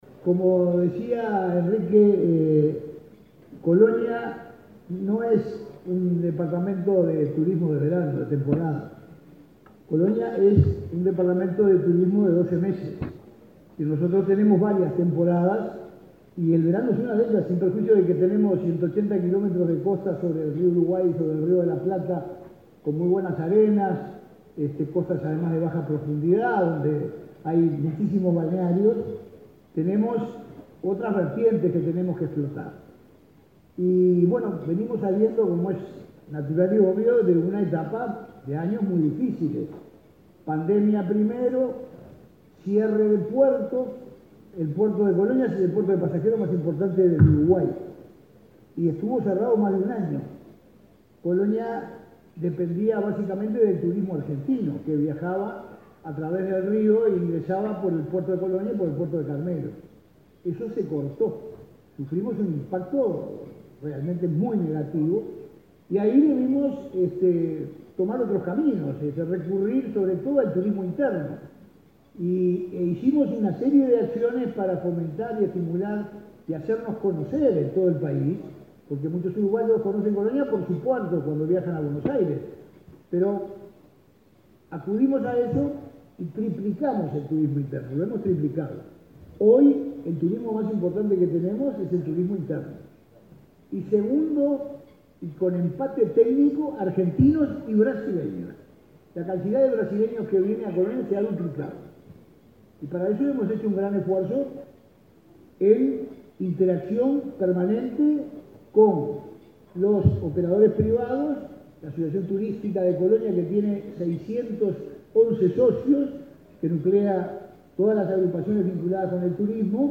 Disertación del intendente de Colonia, Carlos Moreira
El intendente de Colonia, Carlos Moreira, disertó, este jueves 7 en Montevideo, en un almuerzo de trabajo de la Asociación de Dirigentes de Marketing,